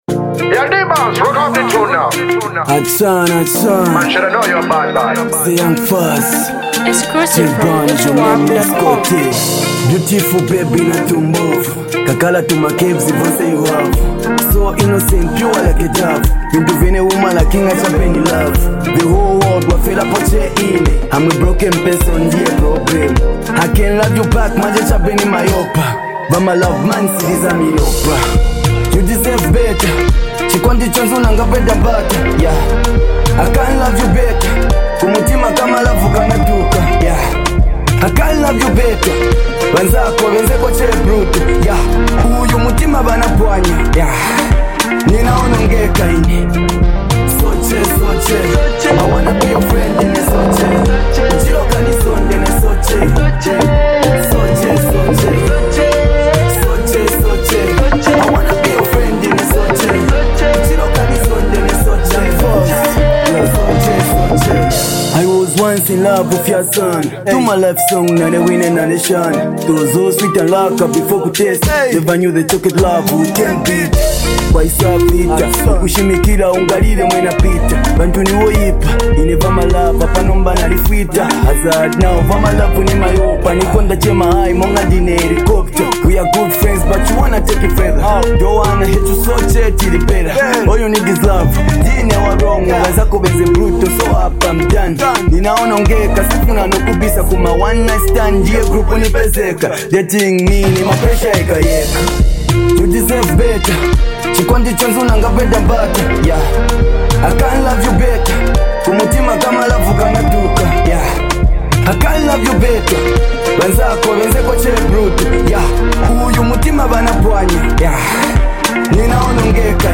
a high-energy track